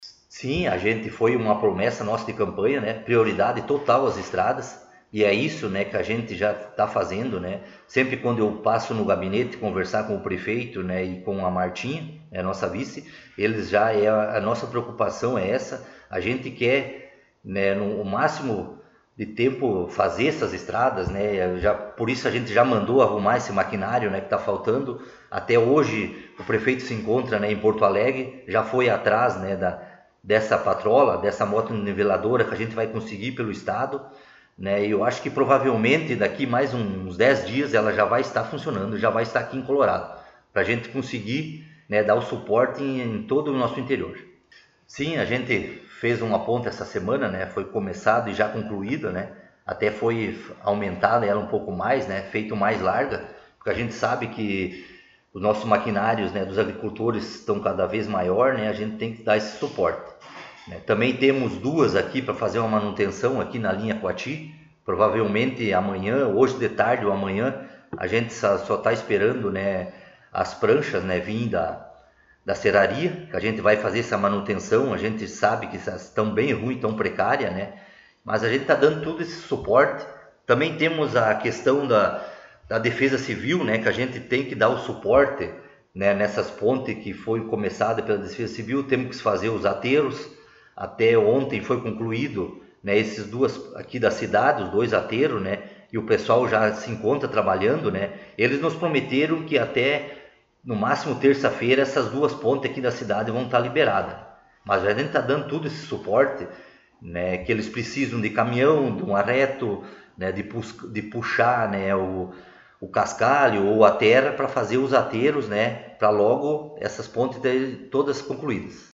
Secretário Municipal de Obras concedeu entrevista